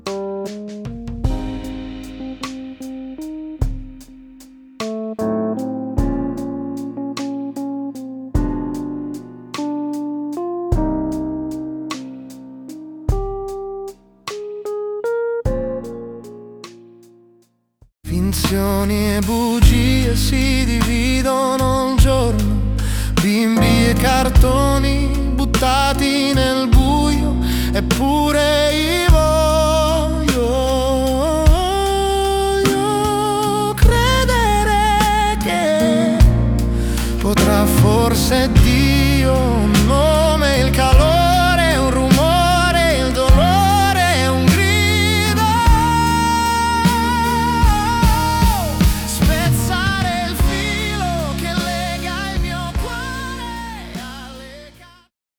From piano demo + lyrics → AI arrangement + vocals.
• Start: original (instrumental melody)
• 00:17: AI arrangement + vocals